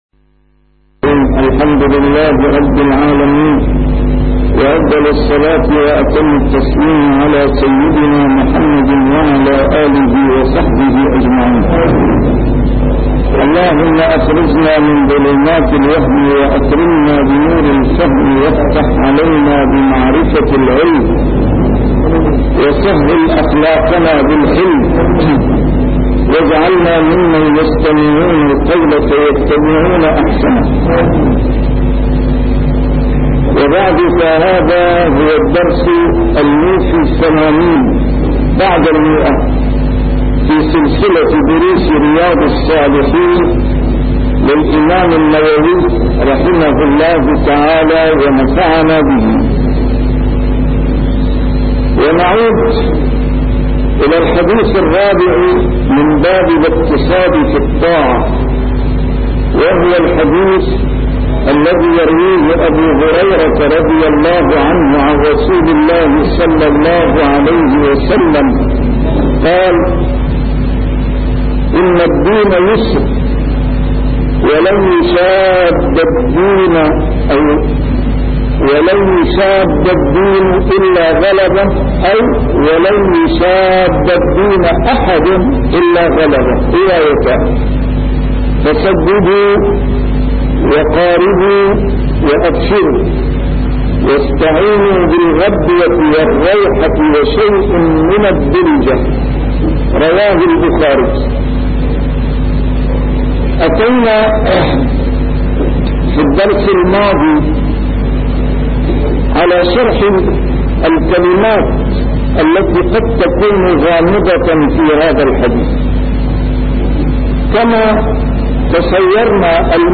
A MARTYR SCHOLAR: IMAM MUHAMMAD SAEED RAMADAN AL-BOUTI - الدروس العلمية - شرح كتاب رياض الصالحين - 180- شرح رياض الصالحين: الاقتصاد في العبادة